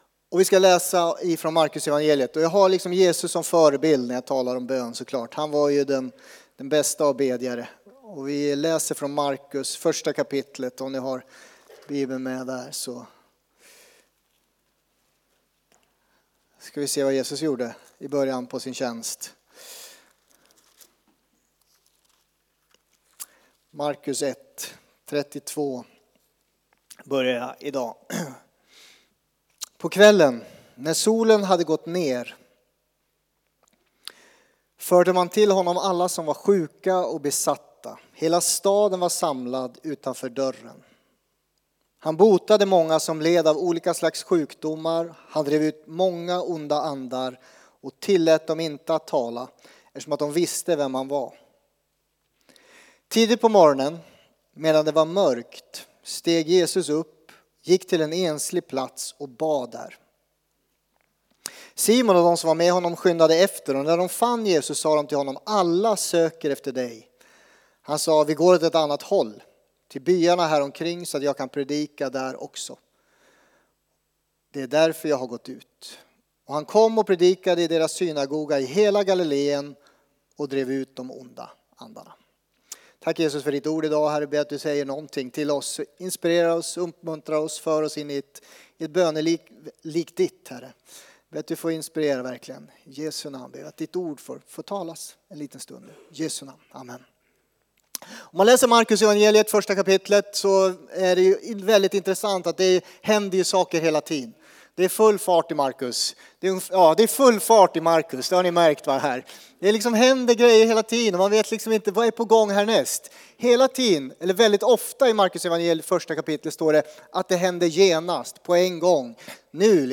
Furuhöjdskyrkan - Predikan